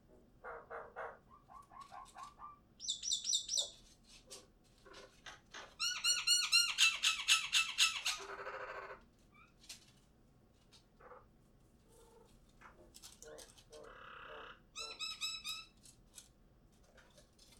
After just one bad night of sleep, common mynas sang fewer and less complex songs.
Common myna singing after a night of disrupted sleep.
comonmynasong-sleepdep.mp3